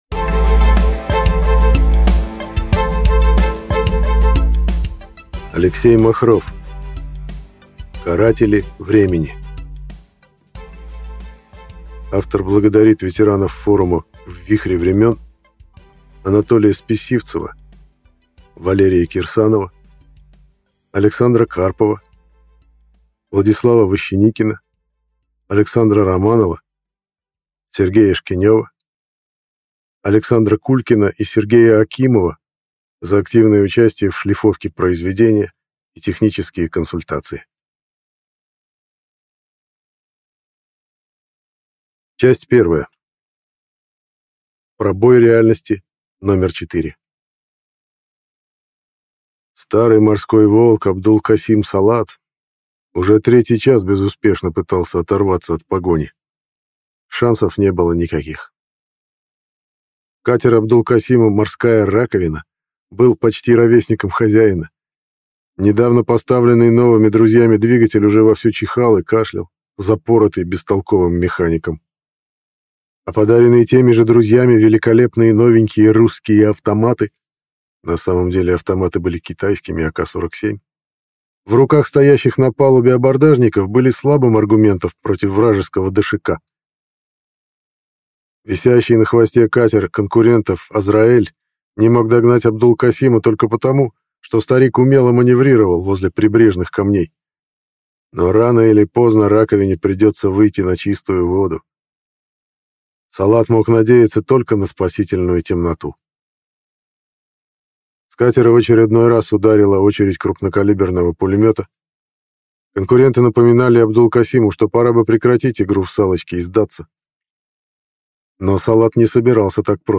Аудиокнига Каратели времени | Библиотека аудиокниг